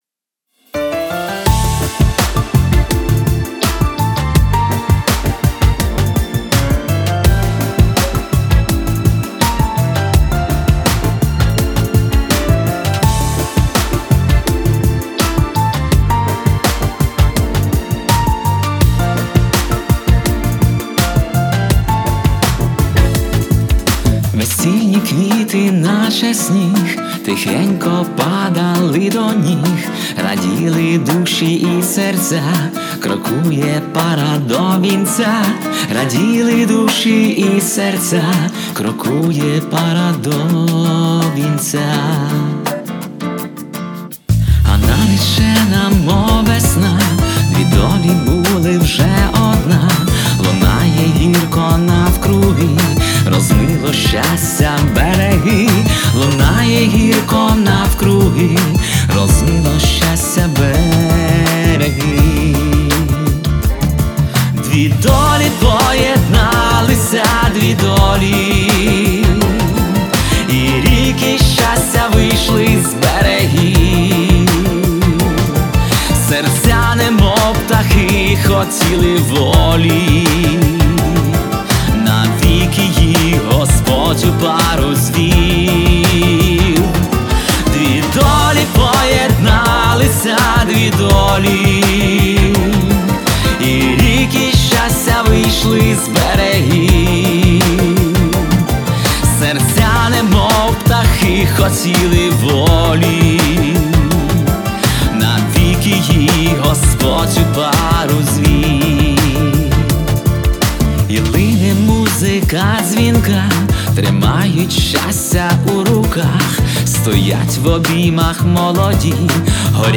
Всі мінусовки жанру Power Ballad
Плюсовий запис